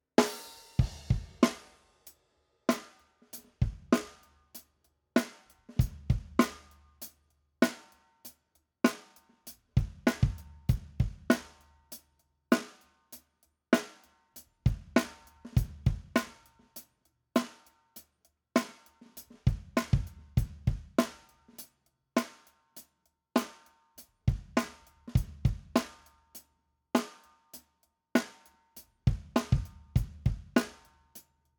Ended up going M/S with the C214 and Fathead. SM57 on top and bottom snare, Rode M3 on the hats, Beta 52 on the kick, and the SM7b on the tom.
Not in love with the snare sound, but that's what he wanted...so it'll do.
Snare does sound pretty weird there, but everything else sounds like the potential is there :thu:
DrumSample.mp3